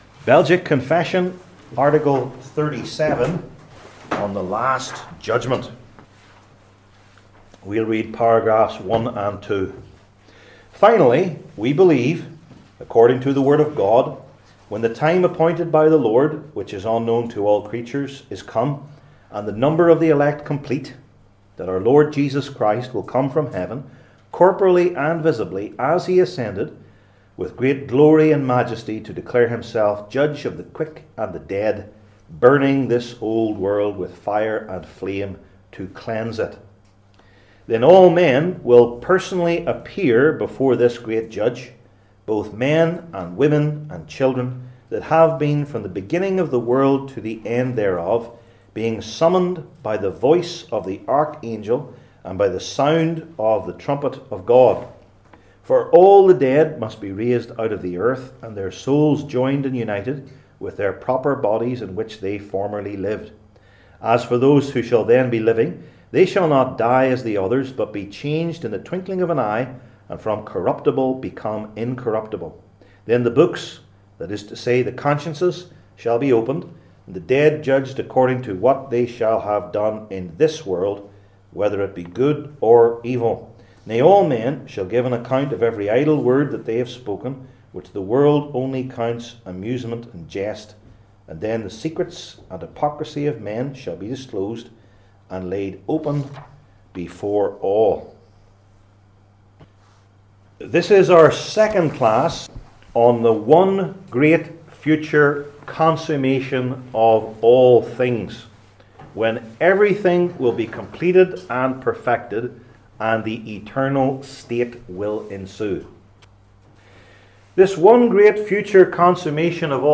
Passage: Romans 8:14-30 Service Type: Belgic Confession Classes